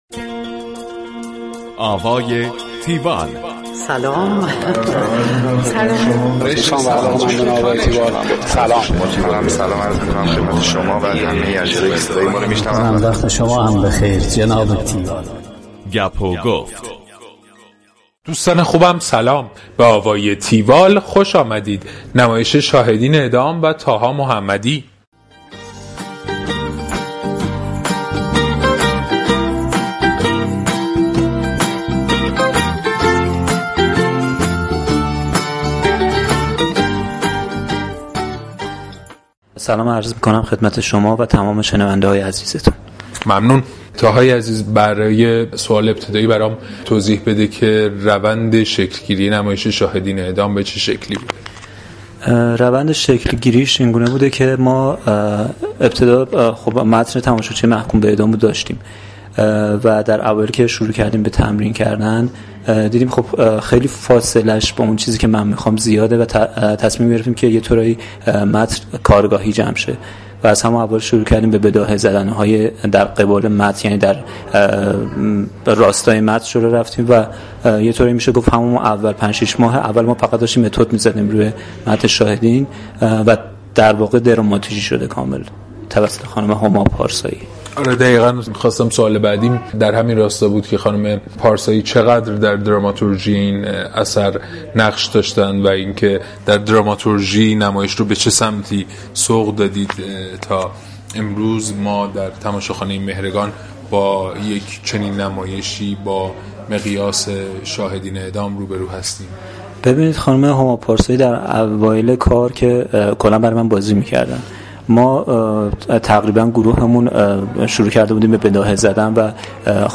گفتگو کننده